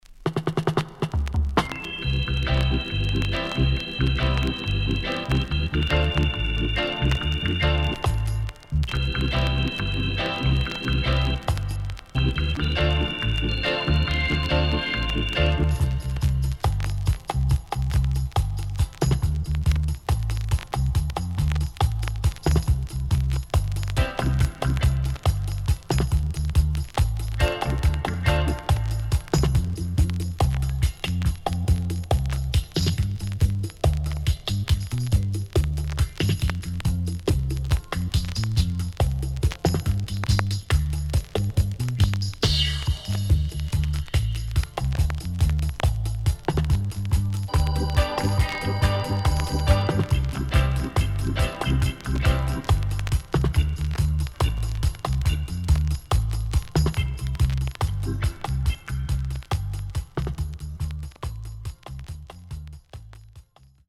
HOME > DUB
SIDE A:全体的にノイズ入ります。